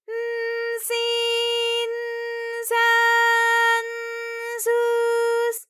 ALYS-DB-001-JPN - First Japanese UTAU vocal library of ALYS.
s_n_si_n_sa_n_su_s.wav